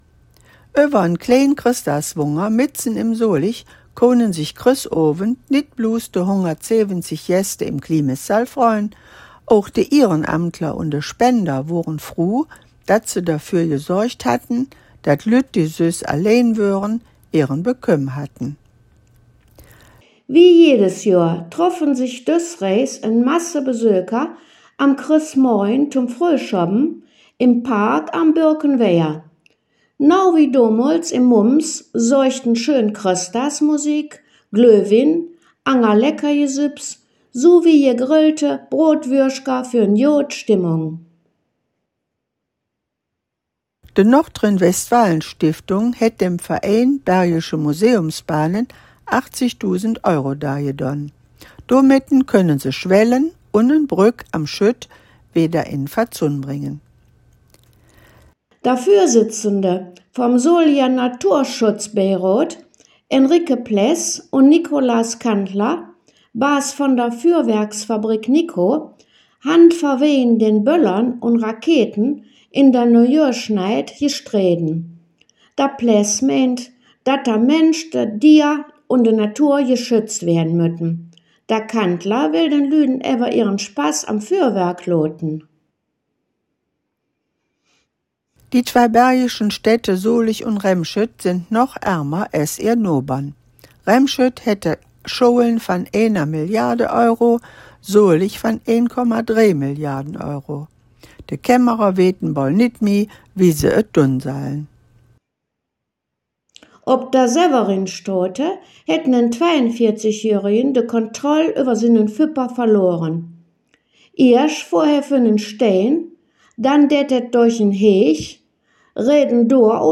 Solinger Platt Nachrichten (26/01) - Studiowelle 2
Nöüegkeïten op Soliger Platt